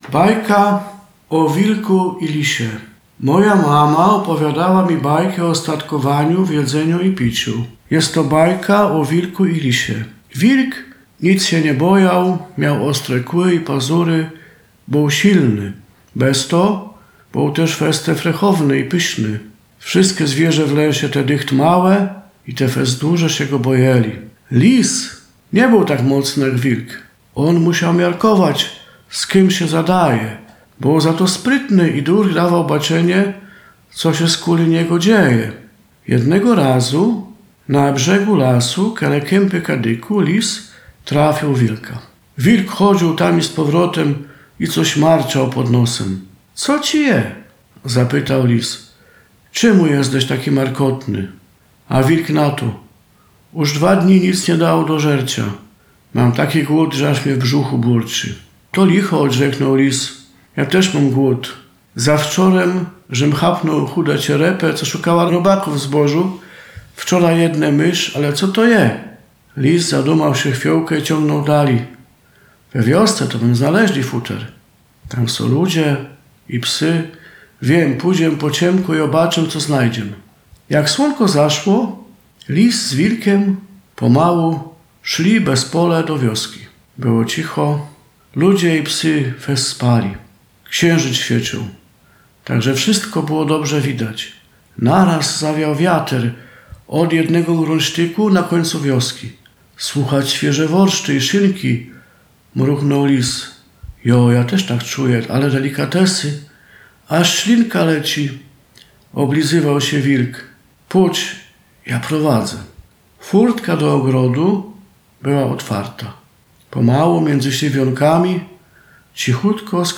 Opowiada